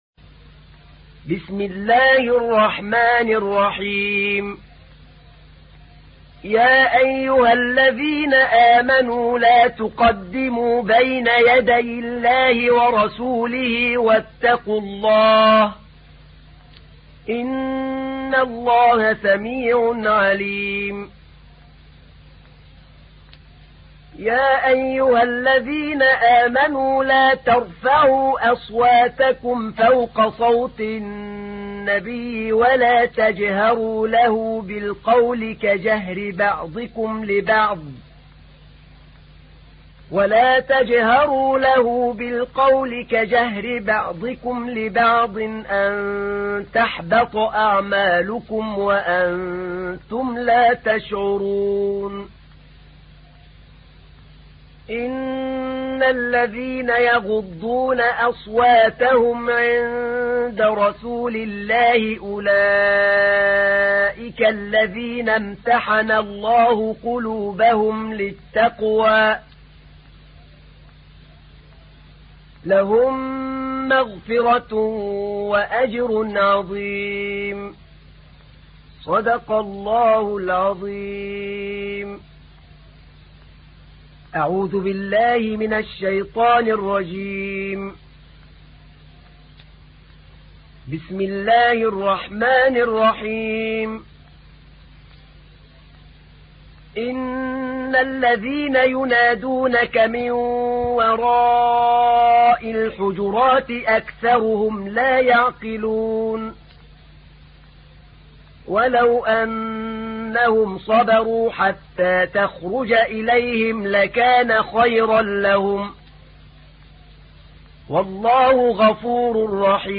تحميل : 49. سورة الحجرات / القارئ أحمد نعينع / القرآن الكريم / موقع يا حسين